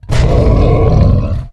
Divergent/boar_aggressive_2.ogg at main
boar_aggressive_2.ogg